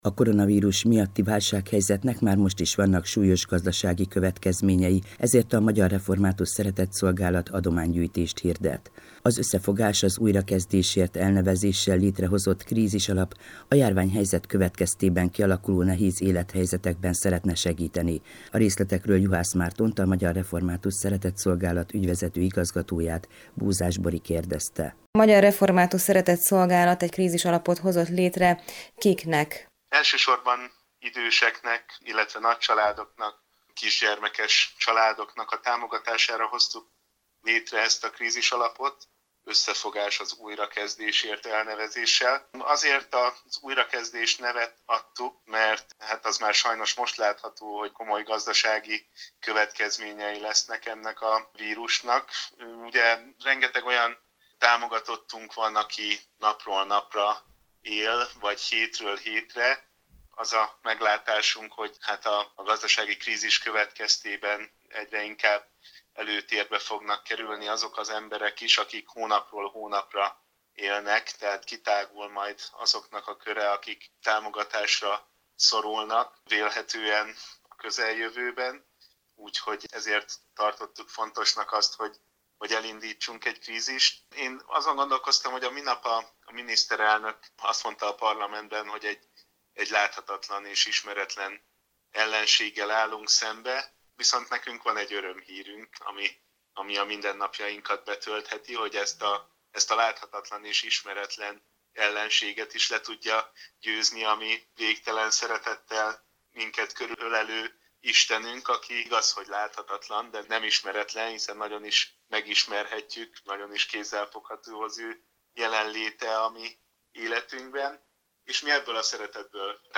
készített interjút az Európa Rádióban